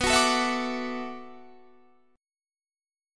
Listen to Bm11 strummed